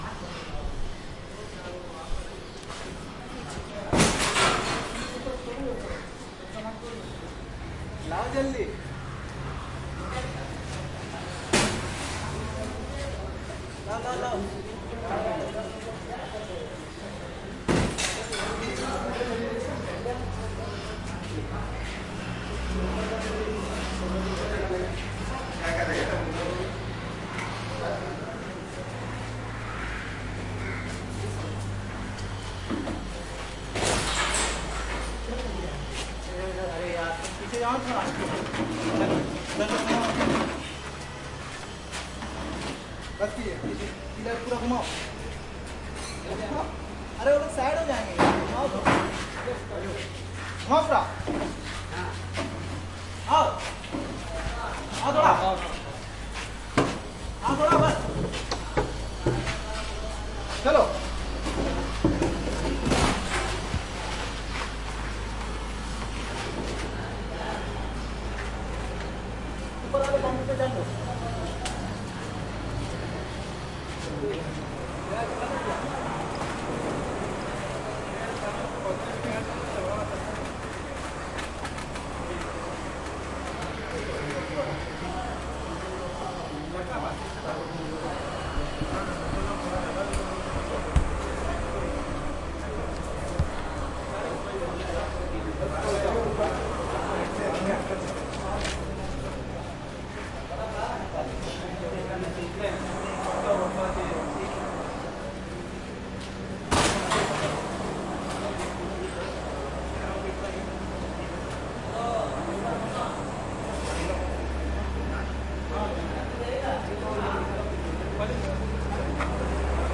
Montreal » construction workers shovelling debris +highway bg Montreal, Canada
描述：construction workers shovelling debris +highway bg Montreal, Canada.flac
标签： shovelling construction workers debris
声道立体声